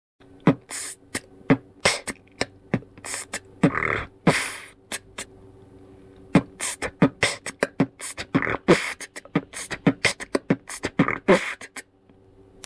2) b tss t b kch t k/ b tss t brr pf t t
Второй бит в быстром исполнений крутяг под темп 120 BHP!